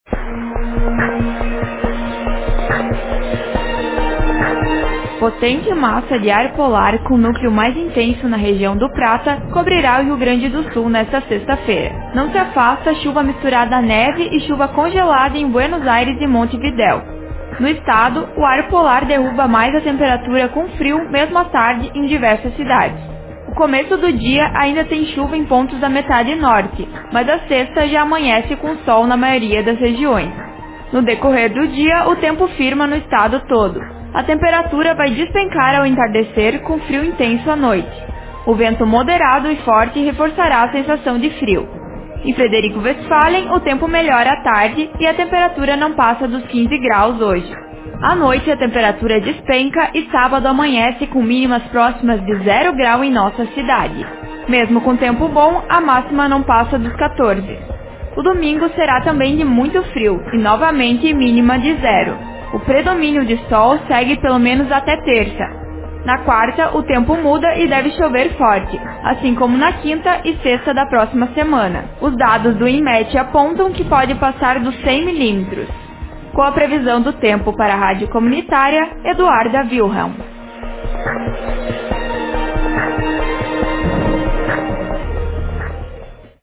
Previsão do tempo: temperatura despenca no RS